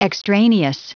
Prononciation du mot extraneous en anglais (fichier audio)
Prononciation du mot : extraneous